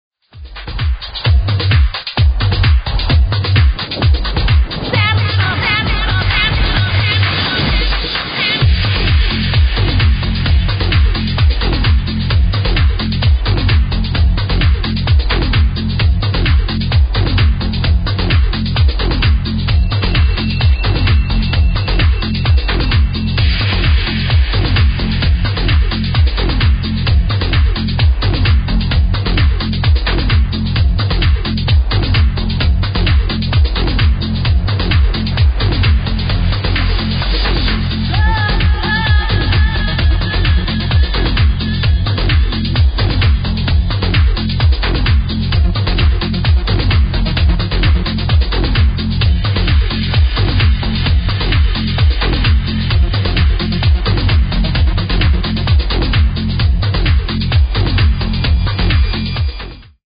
great prog tune
recorded at dancevalley 2005, right before sasha